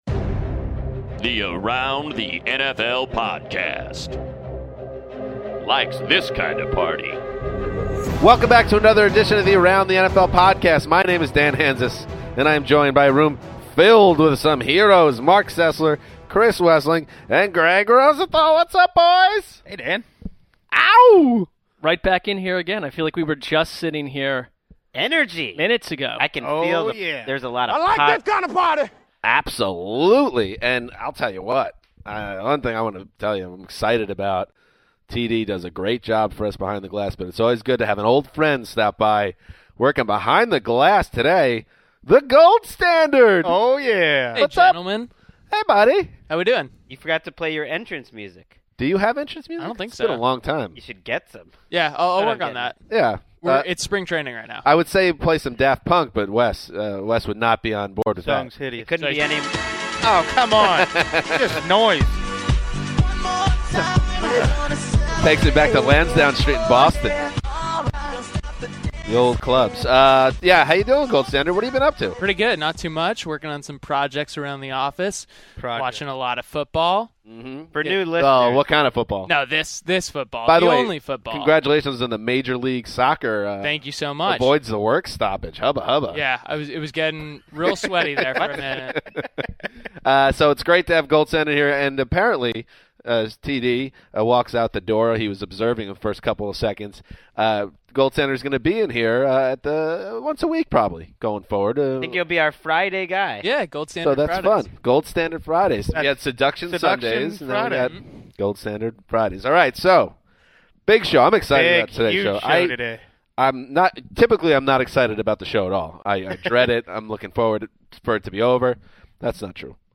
welcomes cornerback Brandon Flowers into the studio